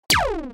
Выстрел из пришельского оружия